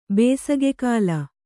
♪ bēsage kāla